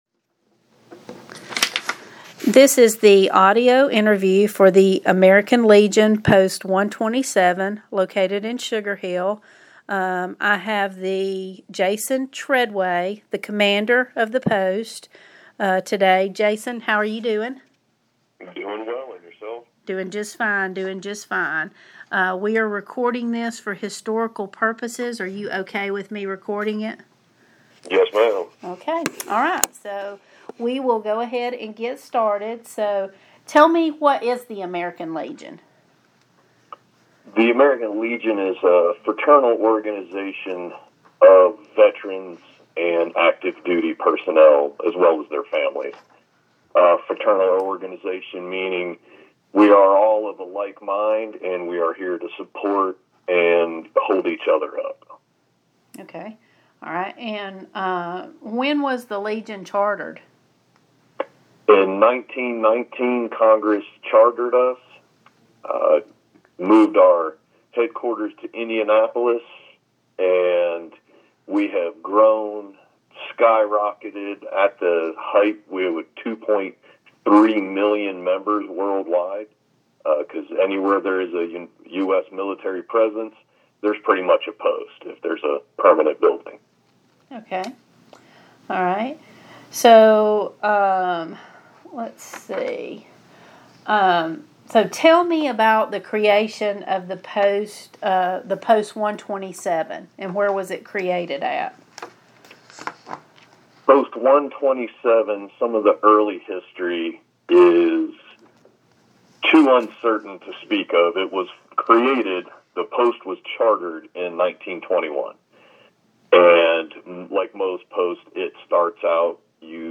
Sugar Hill Subject American Legion Post 127 Oral histories Sugar Hill
Oral History
by phone